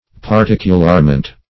Particularment \Par*tic"u*lar*ment\, n.